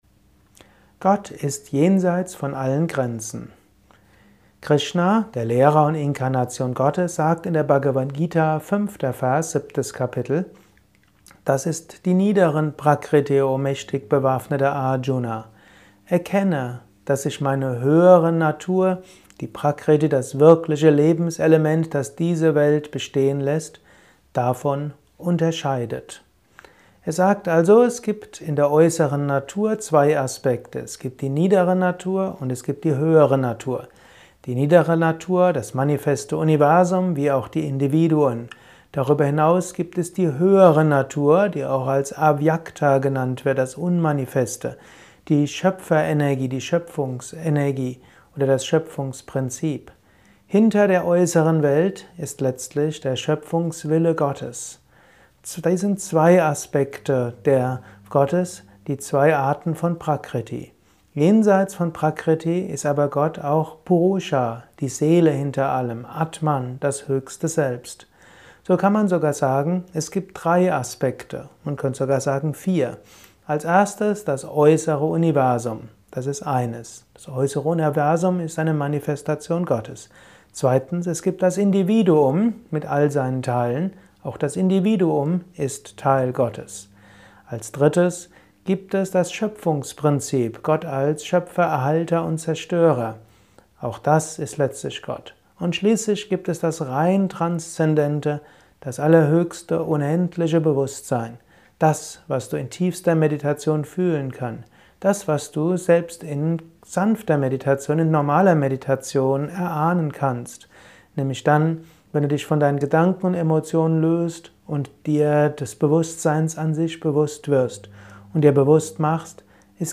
Dies ist ein kurzer Kommentar als Inspiration für den heutigen